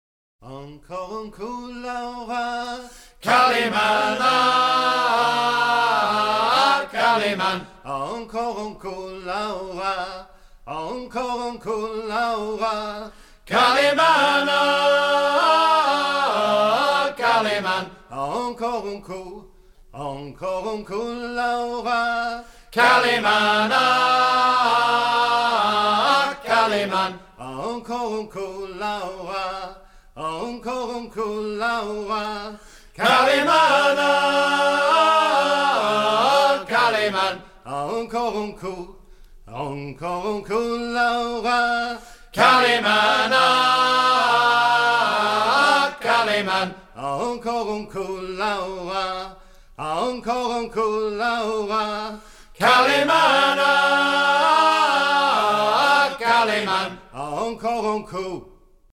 Fonction d'après l'analyste gestuel : à déhaler
Usage d'après l'analyste circonstance : maritimes
Pièce musicale éditée